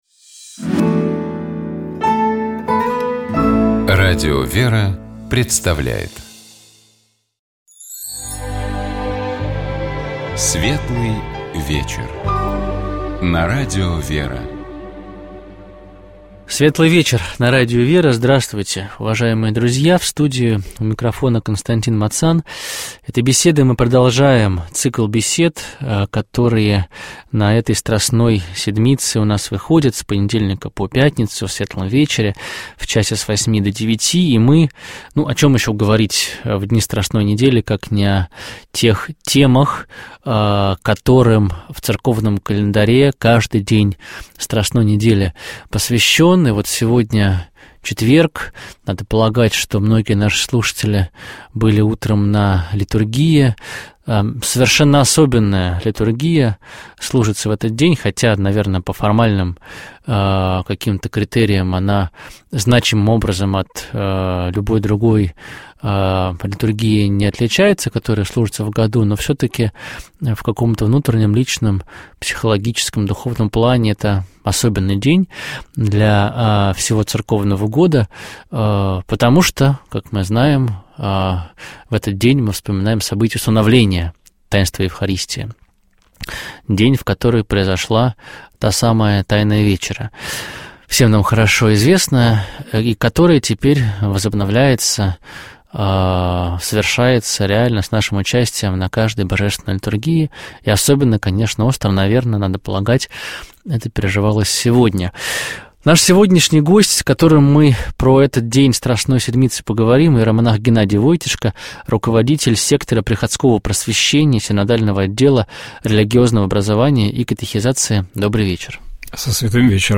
Божественная литургия. 13 апреля 2025г.